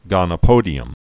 (gŏnə-pōdē-əm)